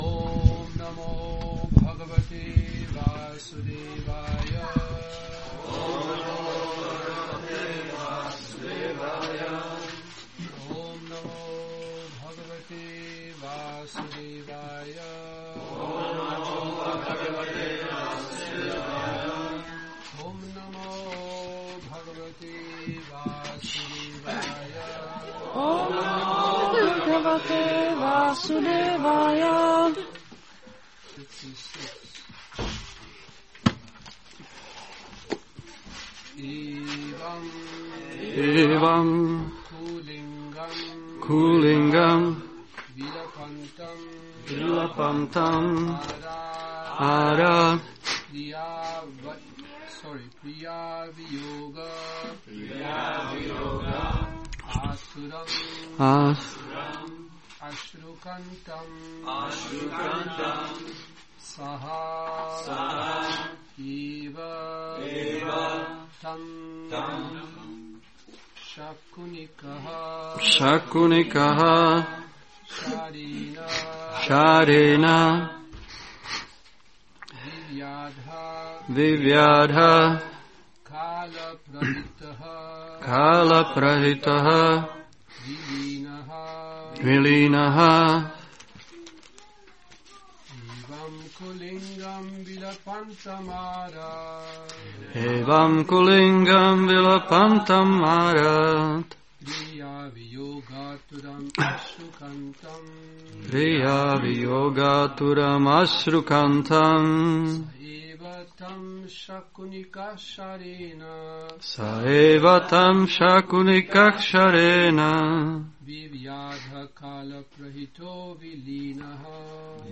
Šrí Šrí Nitái Navadvípačandra mandir
Přednáška SB-7.2.56